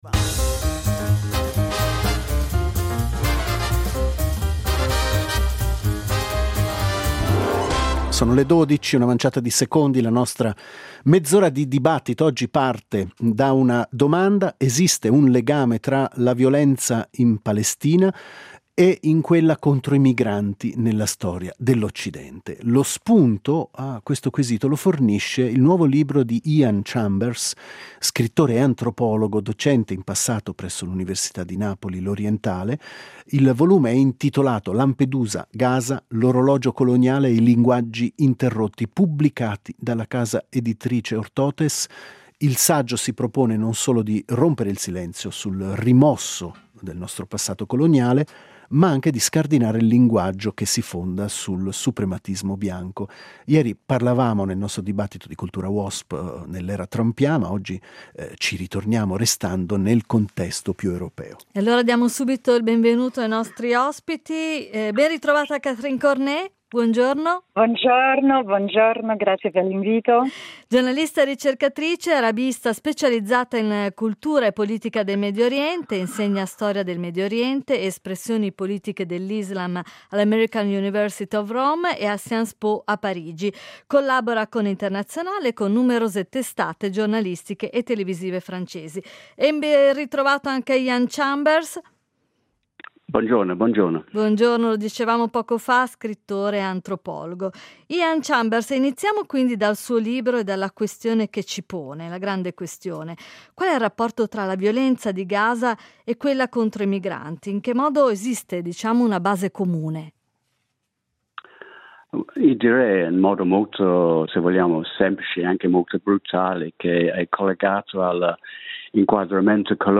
Ad Alphaville ne abbiamo parlato con due ospiti: